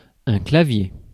Ääntäminen
France: IPA: [ɛ̃ kla.vje]